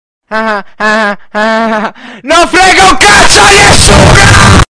eeh eeh non frega Meme Sound Effect
eeh eeh non frega.mp3